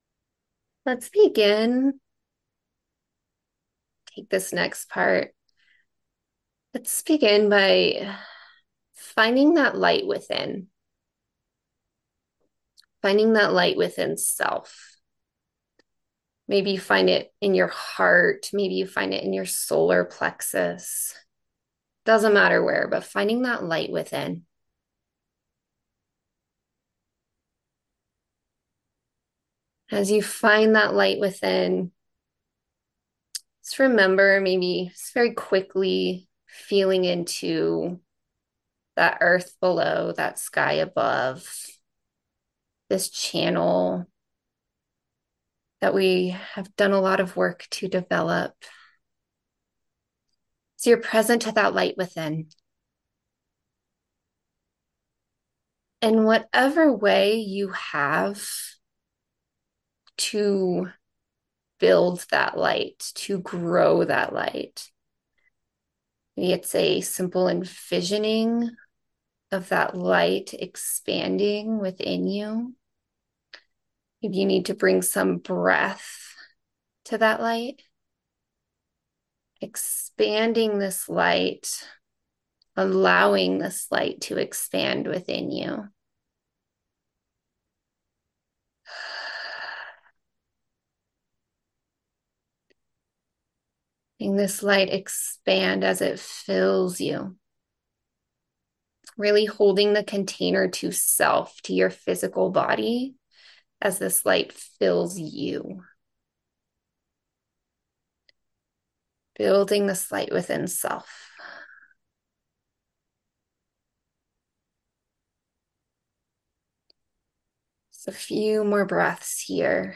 Please recognize that this is a guided audio clip taken directly from Circle.
September-25th-land-connection-meditation.mp3